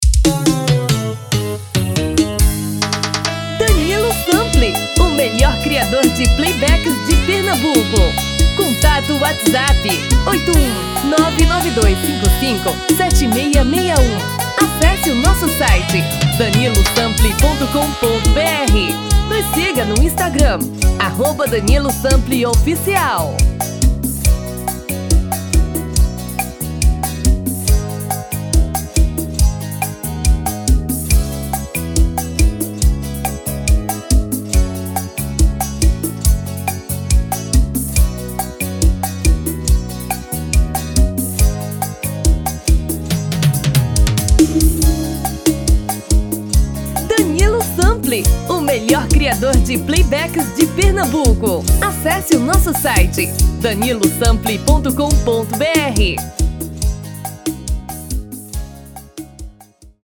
TIPO: Pot-Pourri de 4 músicas sequenciadas
RITMO: Arrocha / Seresta
TOM: Feminino (Original)